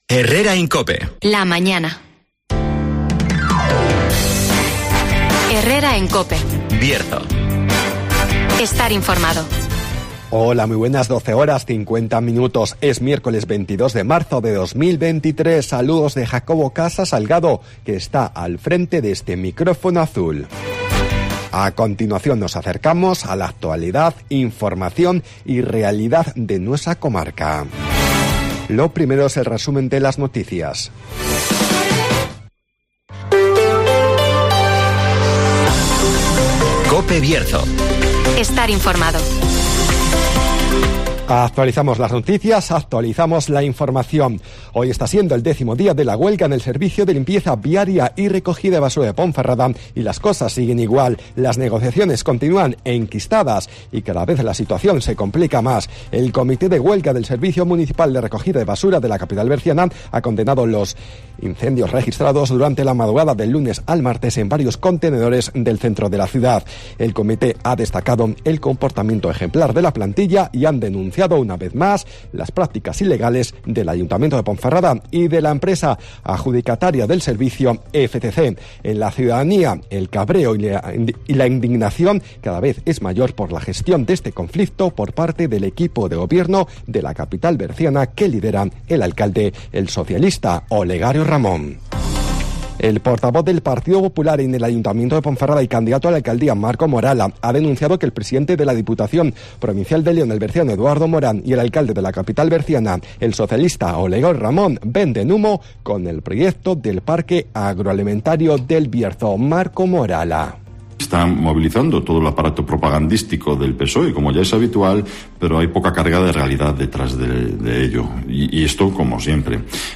Resumen de las noticias, el tiempo y la agenda.